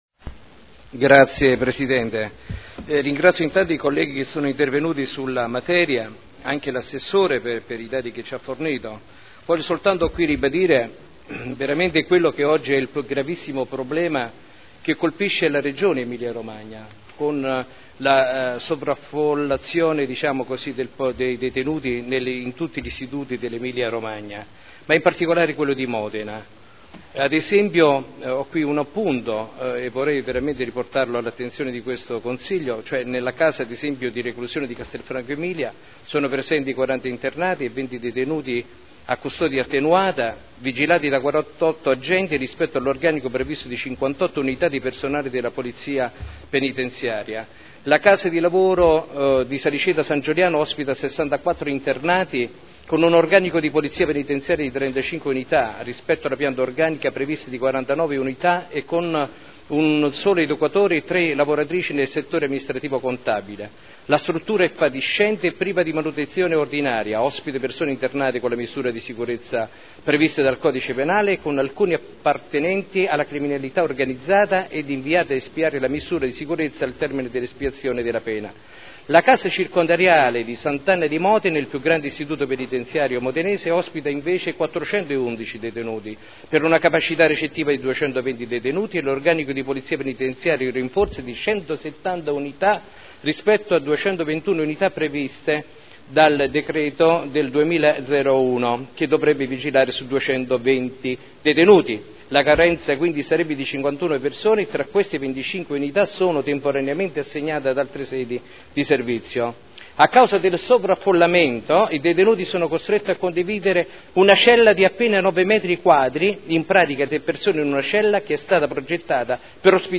Maurizio Dori — Sito Audio Consiglio Comunale
Seduta del 30 gennaio Interrogazione dei consiglieri Dori e Rocco (P.D.) avente per oggetto: “Organici carcere S. Anna” Replica